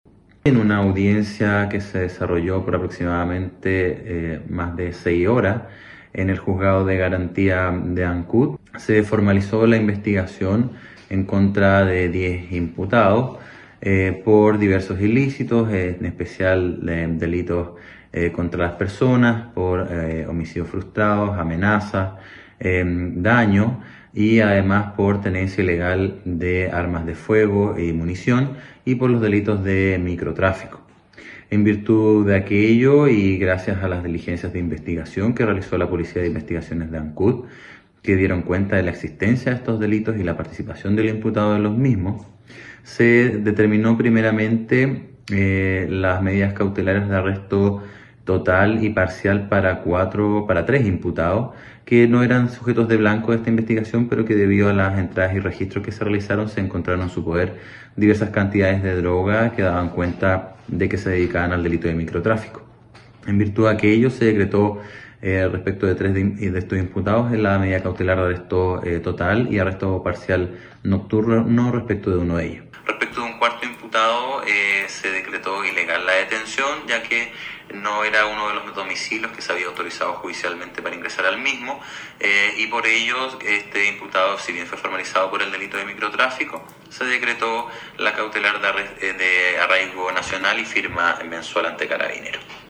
El fiscal Luis Barría, se refirió a la formalización de los 10 imputados señalando las determinaciones del tribunal acerca del grupo de cuatro personas que no tenían participación directa en los hechos investigados.
03-FISCAL-LUIS-BARRIA-IMPUTADOS-BANDAS.mp3